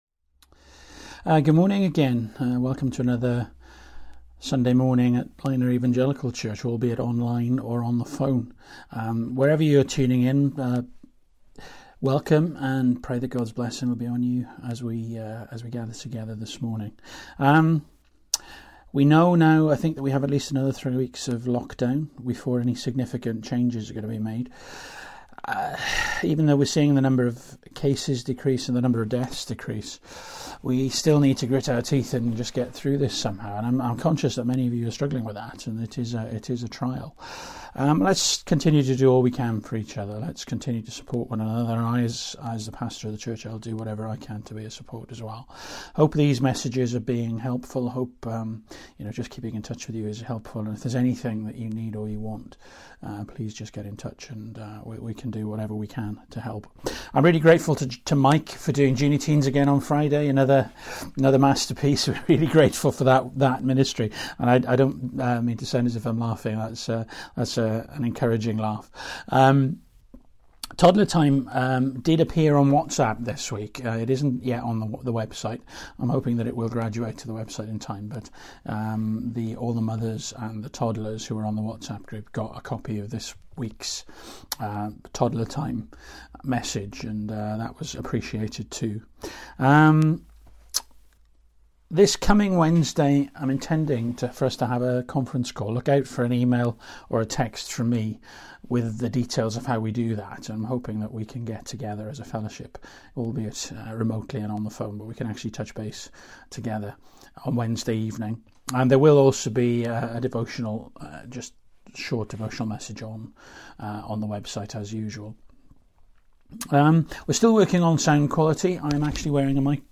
1 Tim 1:1-2 Service Type: Morning Bible Text